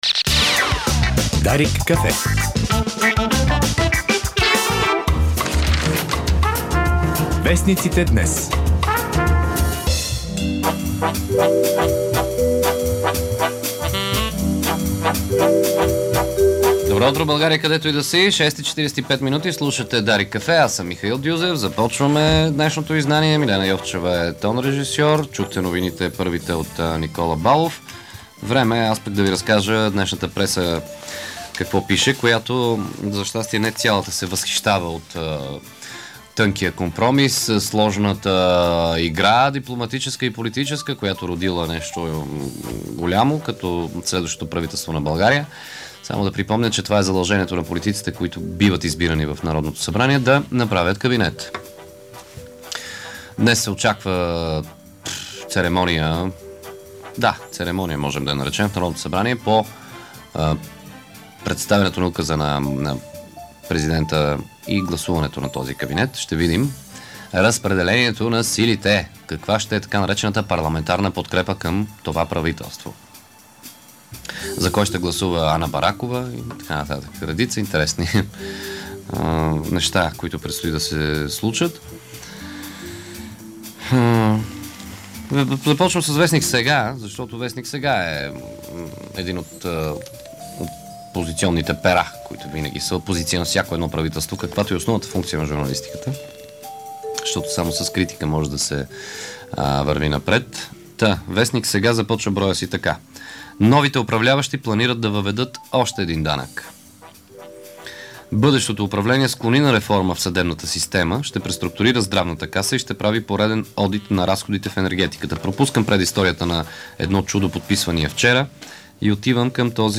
Преглед на печата